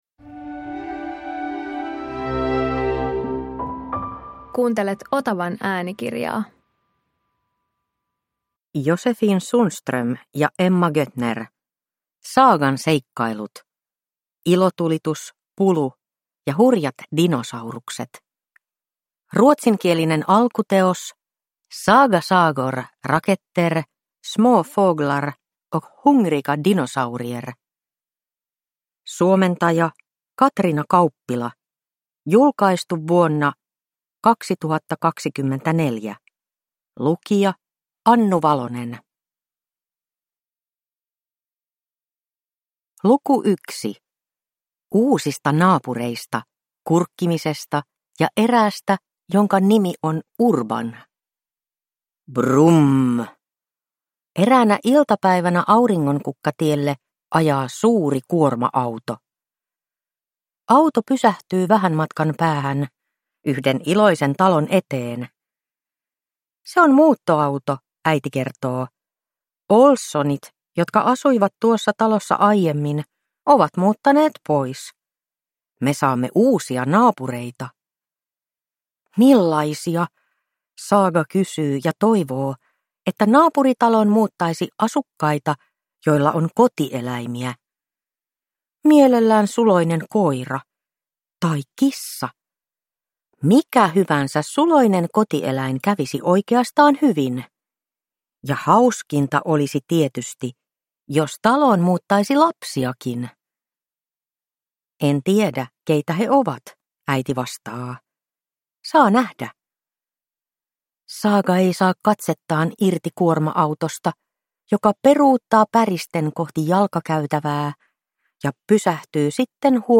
Saagan seikkailut. Ilotulitus, pulu ja hurjat dinosaurukset – Ljudbok